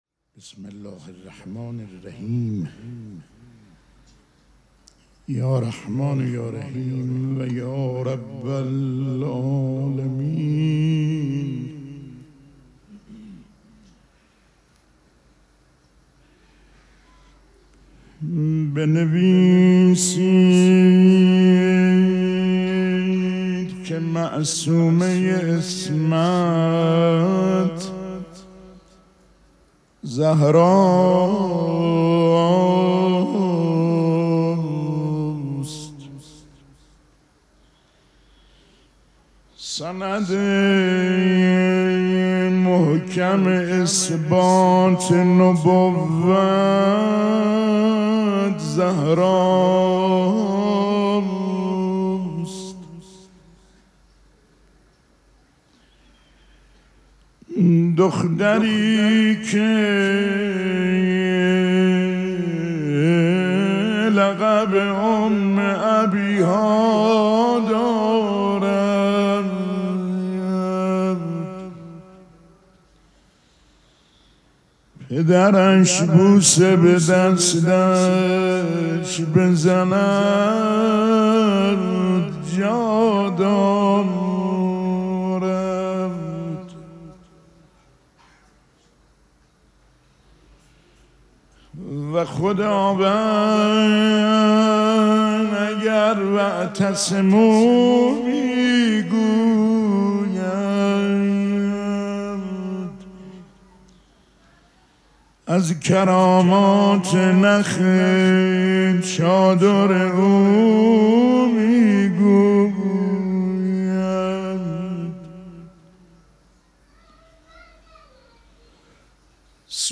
شب دوم مراسم عزاداری دهه دوم فاطمیه ۱۴۴۶
پیش منبر